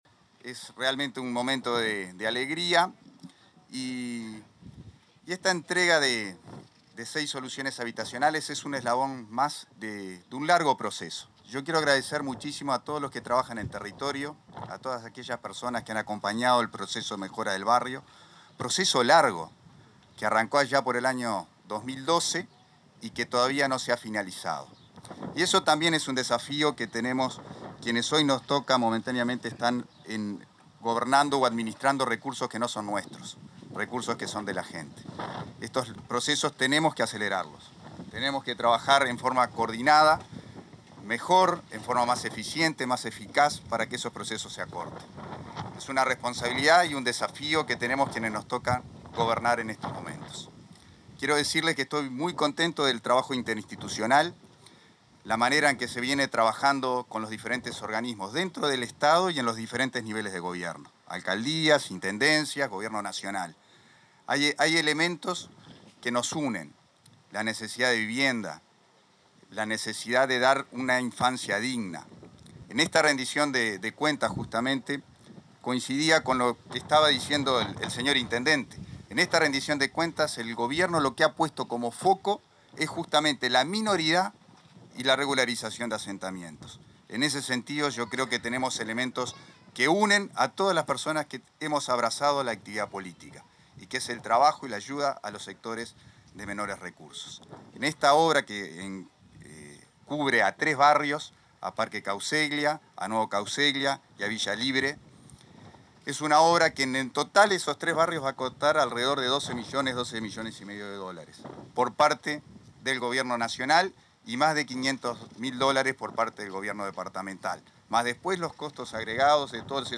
Palabras del subsecretario de Vivienda, Tabaré Hackenbruch
El subsecretario de Vivienda, Tabaré Hackenbruch, participó, este jueves 30, de la entrega de viviendas para realojo en el barrio Cauceglia de